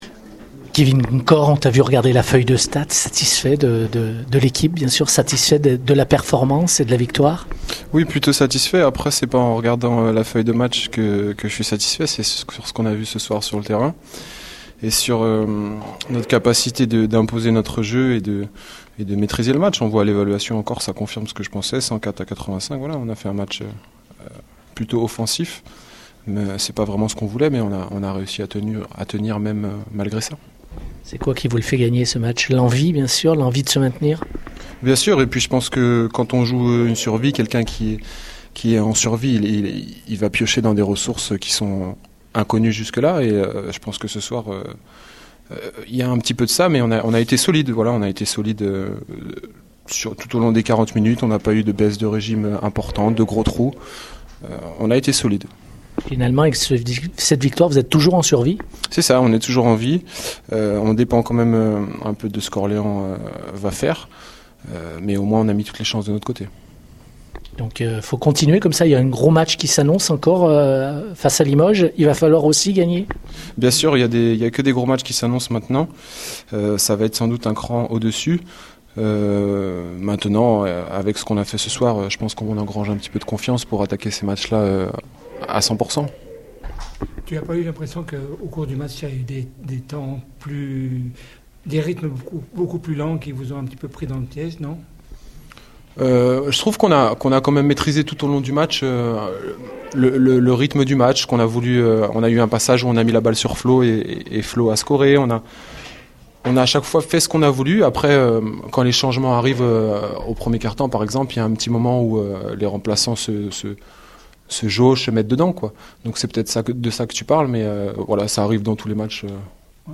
On écoute les réactions d’après-match au micro Radio Scoop